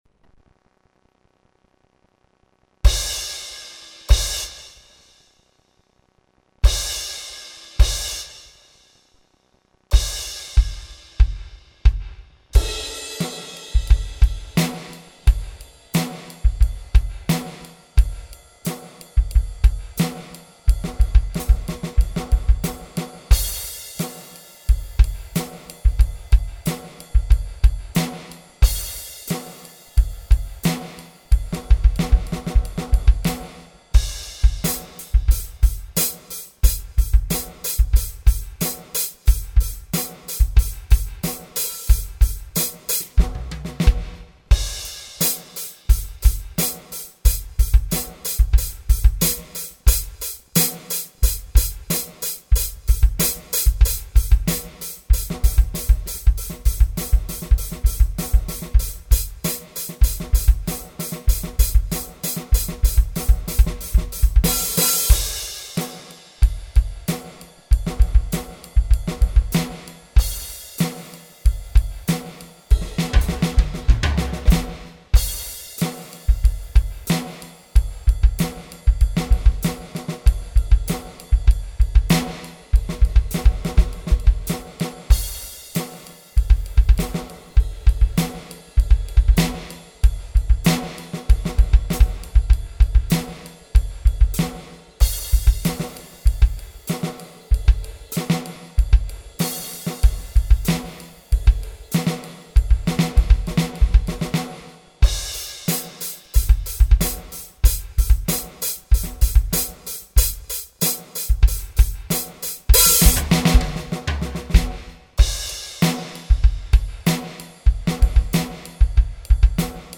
Per registrare i pezzi che qui allego ho usato una recentissima Roland TD12k, con l'aggiunta di un terzo piatto ed un secondo pad da 10 per il timpano.
Perdonatemi quindi se c'è un po' di rumore di fondo, così come qualche errore di esecuzione (ma è bello così! Non vorrete certo ascoltare una batteria artificiale o dei pezzi ritoccati!)
Io ho cercato di condensare molti esempi di paraddidle in un filo logico che in qualche modo le legasse.